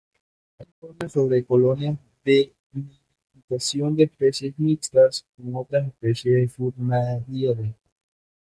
Pronounced as (IPA) /ˈotɾas/